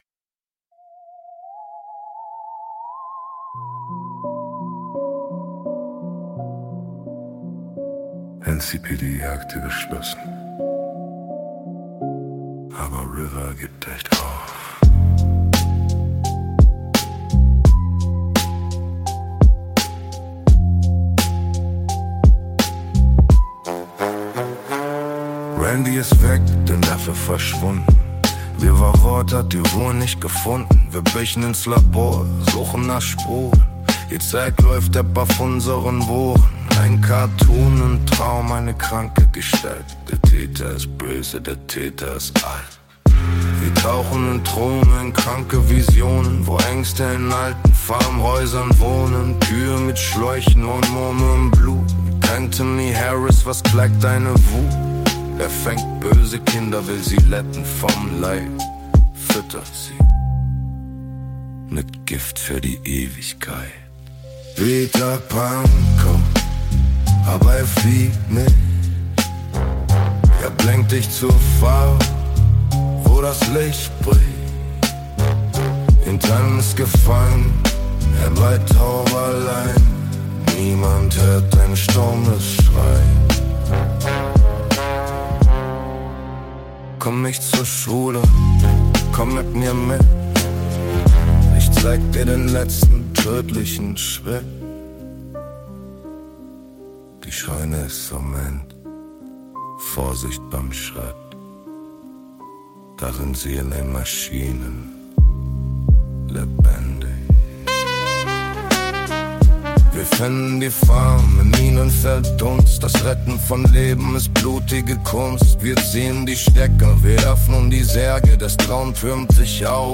Genre: jazz-hop, noir, downtempo
// Album cover and music were created… using AI support…